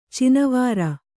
♪ cinavāra